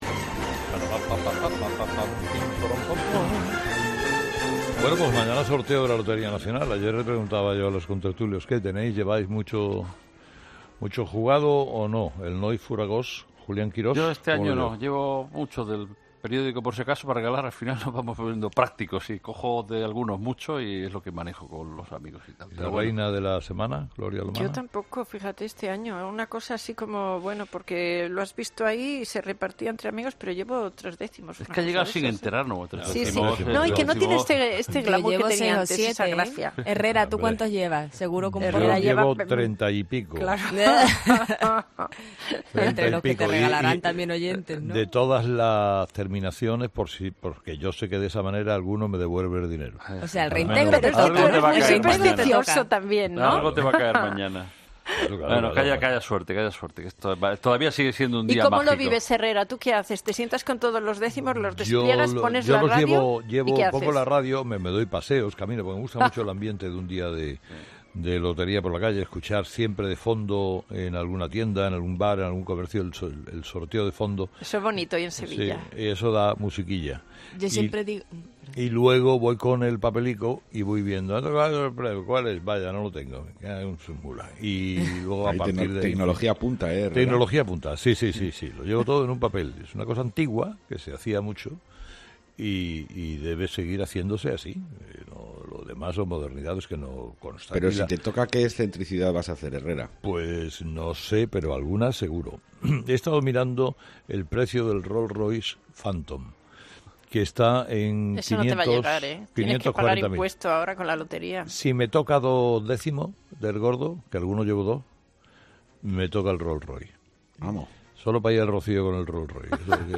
El director del espacio matutino ha comenzado preguntando a los contertulios por los décimos que llevaban y si estaban jugando mucho o no. "Yo este año no"